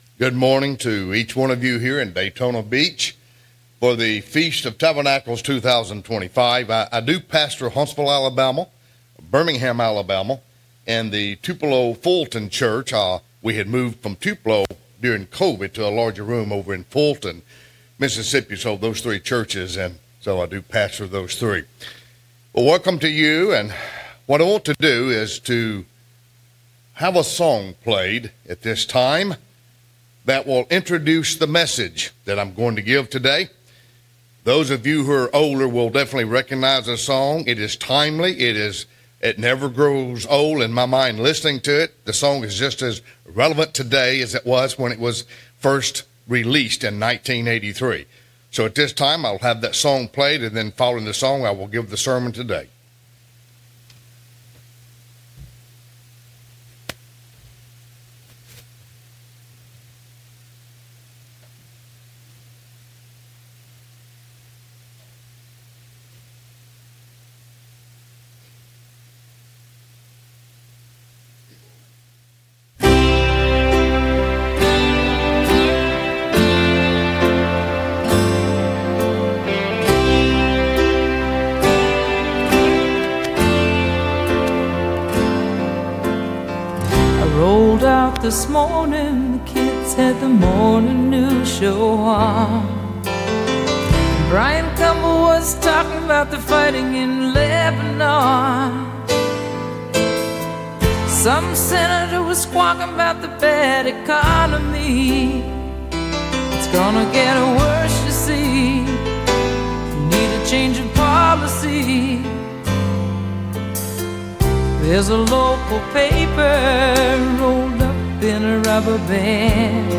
Given in Daytona Beach, Florida